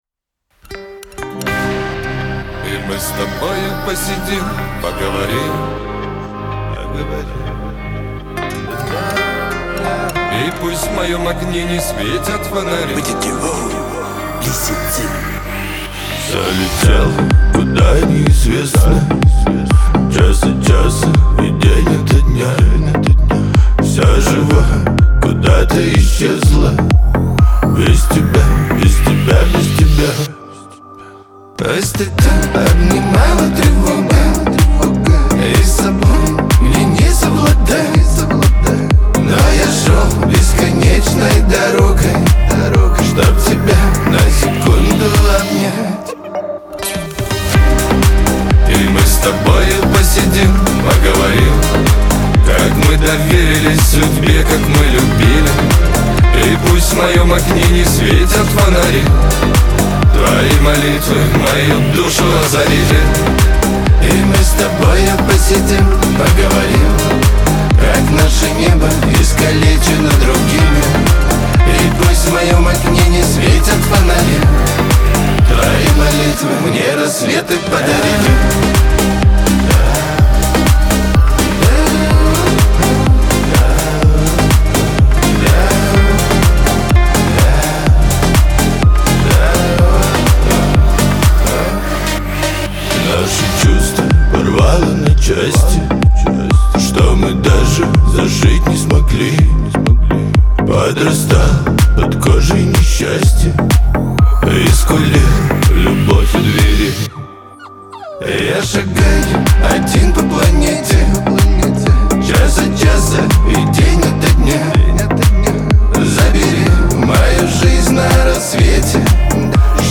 Шансон
Лирика , диско